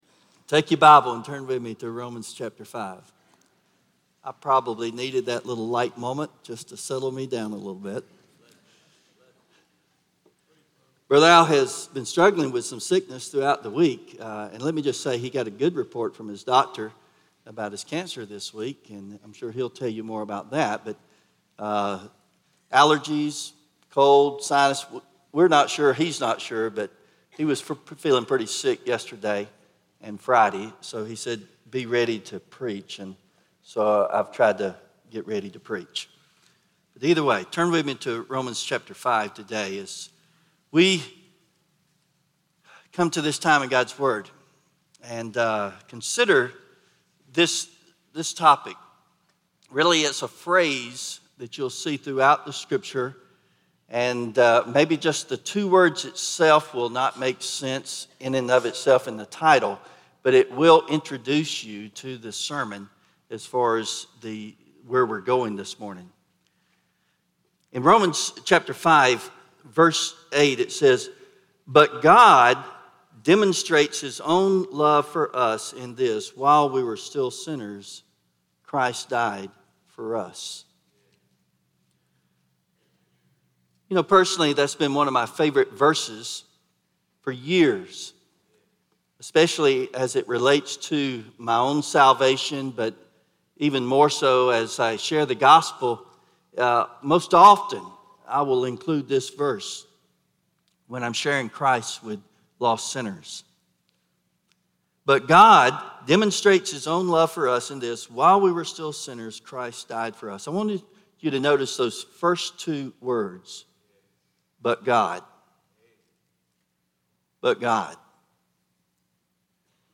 Romans 5:8 Service Type: Sunday Morning 1.